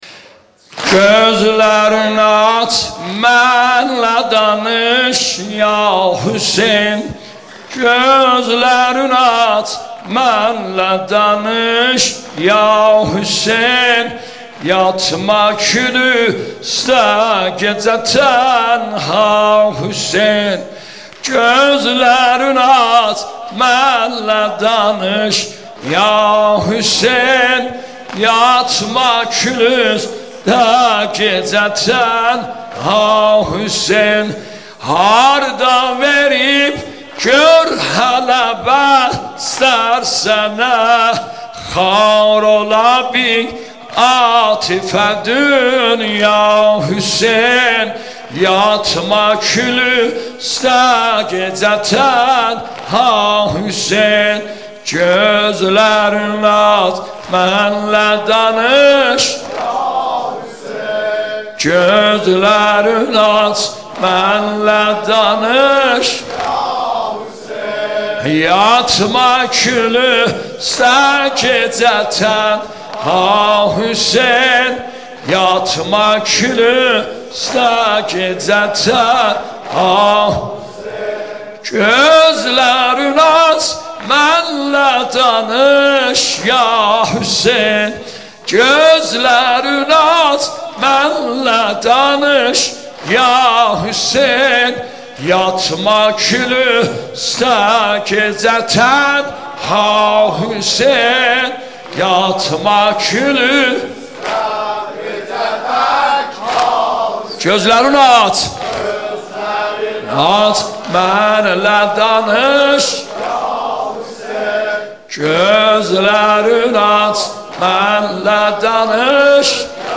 مداحی
نوحه ترکی شنیدنی